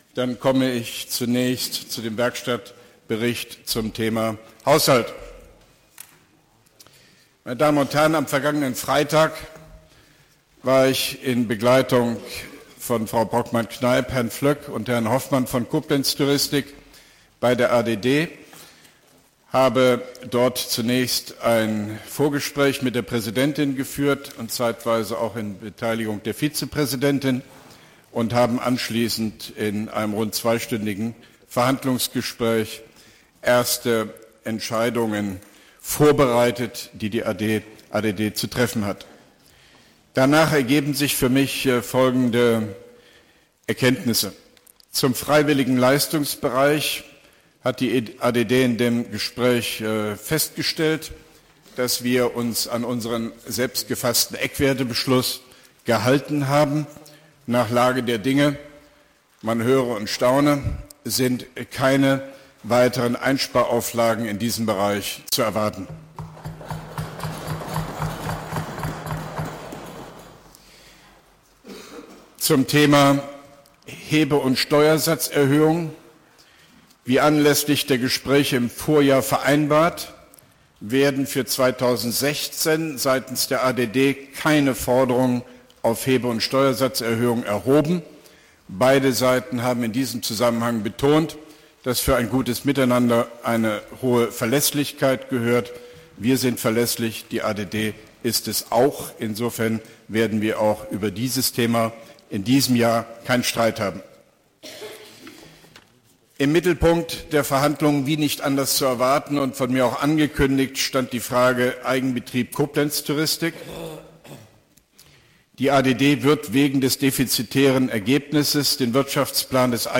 Auszug: Werkstattbericht zum Koblenzer Haushalt von OB Hofmann-Göttig vor dem Koblenzer Stadtrat, 28.01.2016